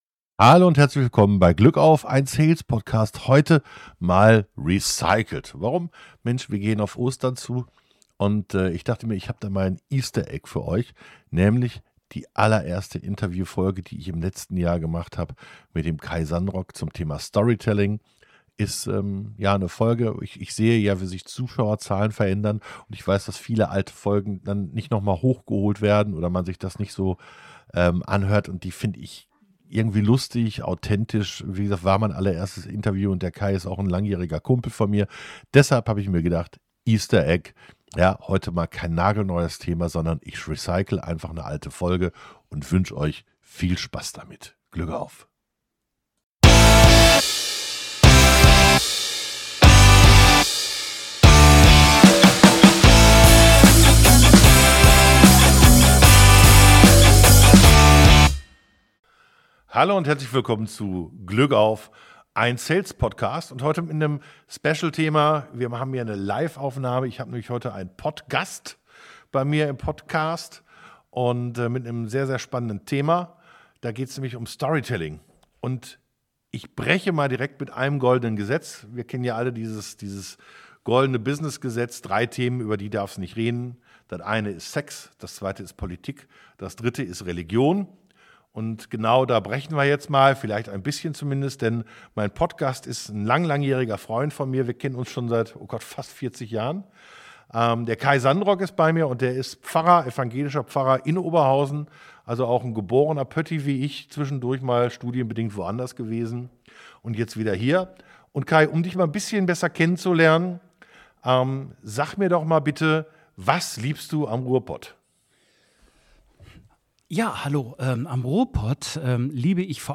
Denn mein Interviewgast ist nicht nur ein Alter Freund sondern zudem noch Pfarrer - was dem ganzen einen ganz besonderen Blick gibt, ohne religiös zu sein.